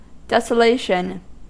Ääntäminen
IPA : /ˌdɛsəˈleɪʃən/